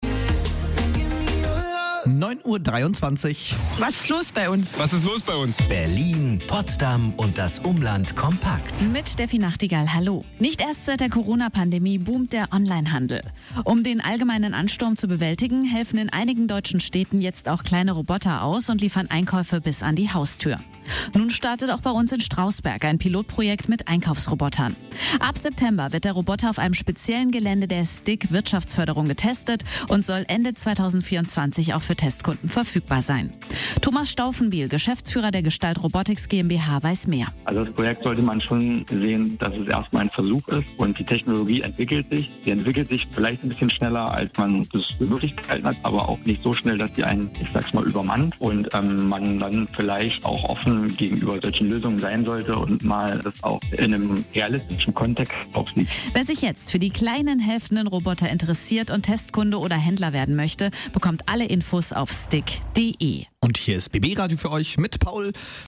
Presse - myLOG im Radio und in der Märkischen Oderzeitung - myLOG MOL- Ein 5G-gesteuertes Logistiksystem zur Stabilisierung des Einzelhandels in Märkisch Oderland.